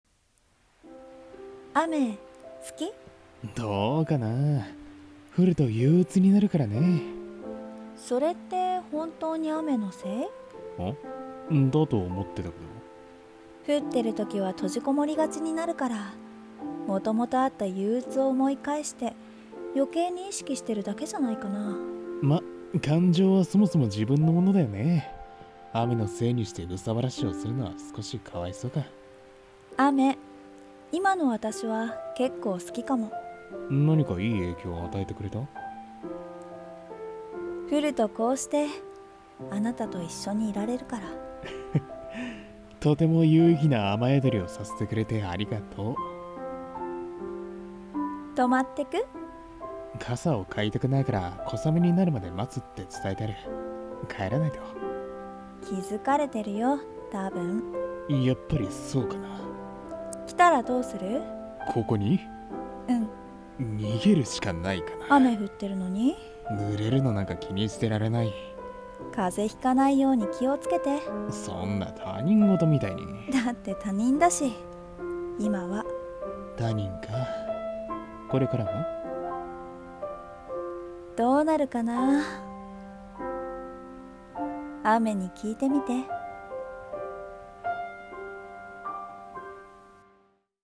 雨の間に、まにまに。【二人声劇】 演◆